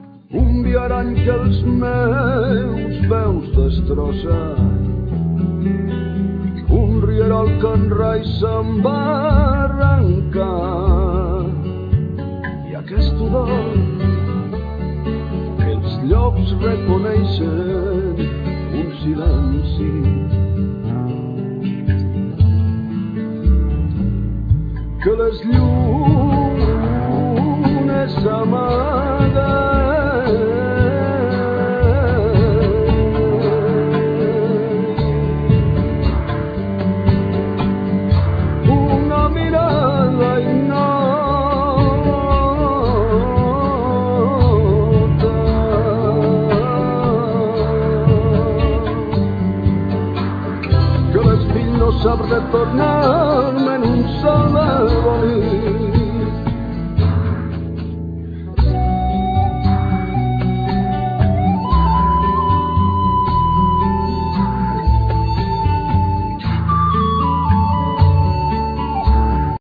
Vocal,Percussions
Dolcaina,Gralla,Cromorn,Gaida bulgara i xirimia